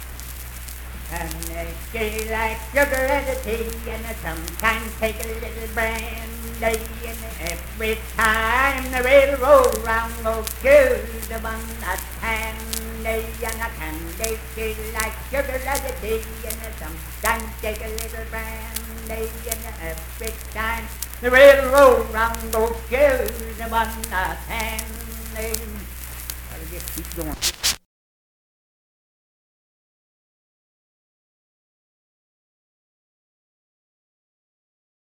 Unaccompanied vocal music performance
Children's Songs
Voice (sung)